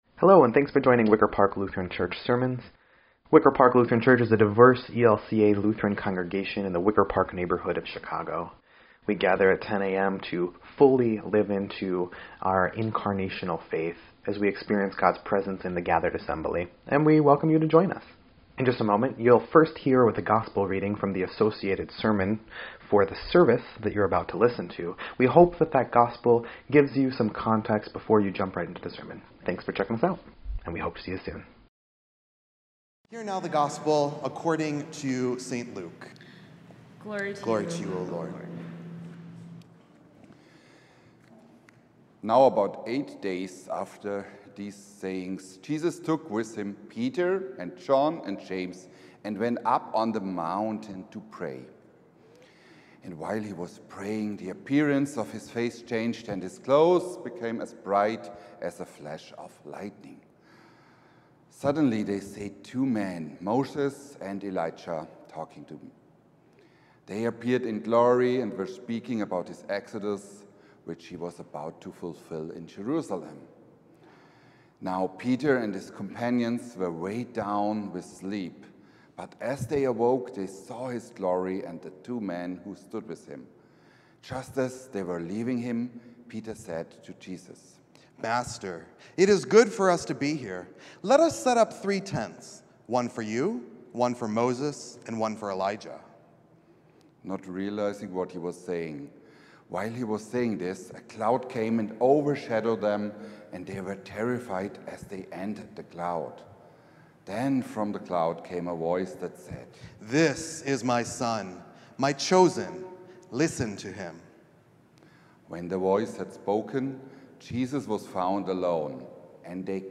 3.2.25-Sermon_EDIT.mp3